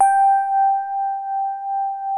E-PIANO 1
TINE SOFT G4.wav